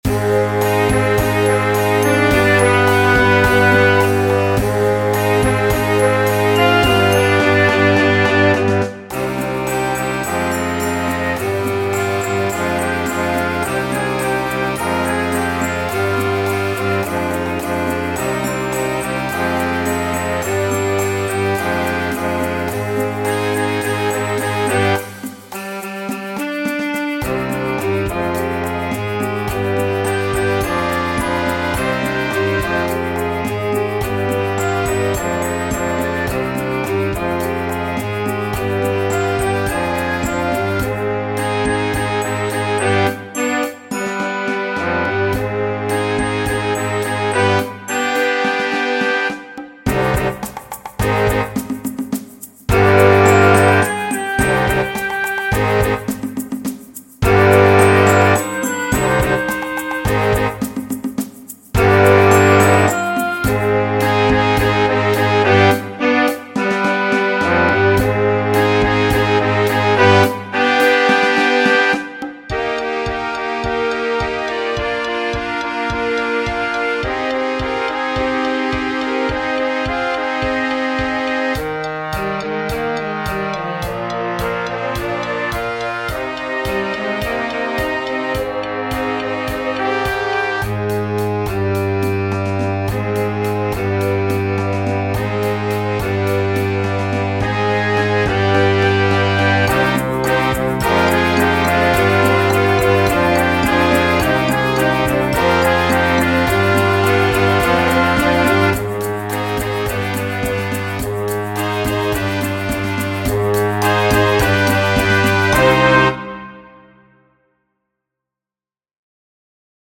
Arrangement facile pour jeune orchestre